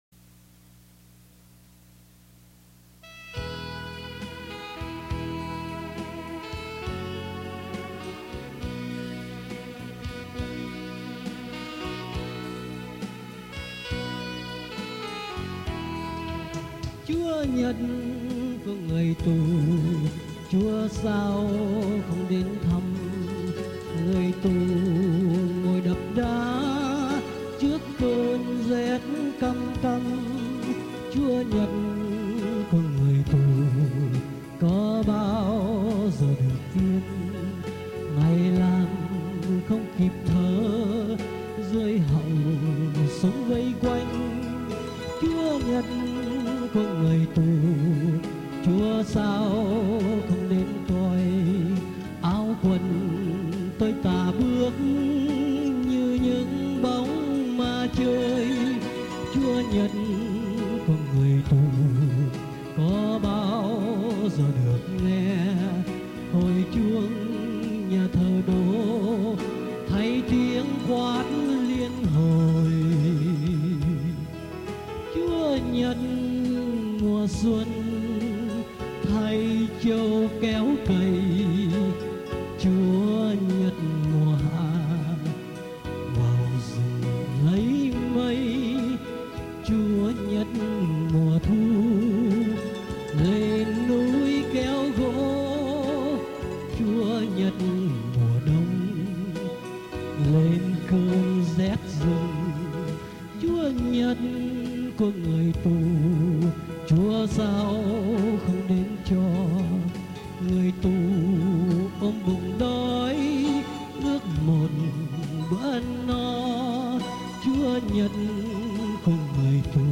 tù khúc